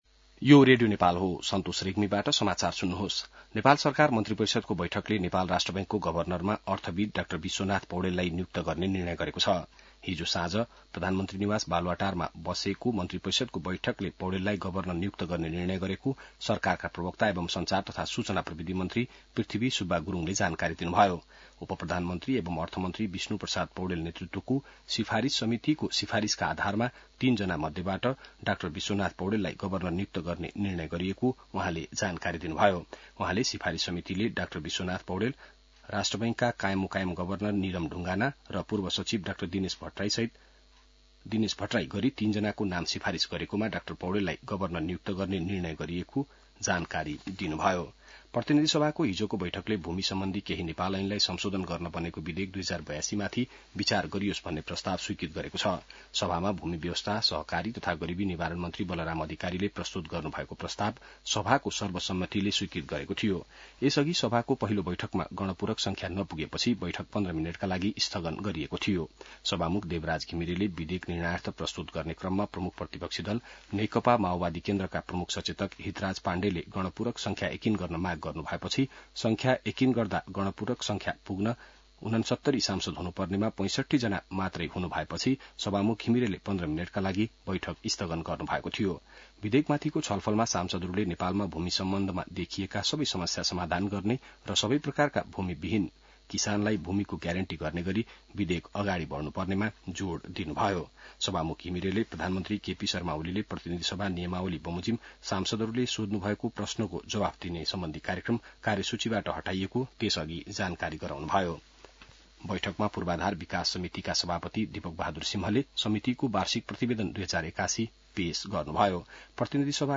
बिहान ६ बजेको नेपाली समाचार : ७ जेठ , २०८२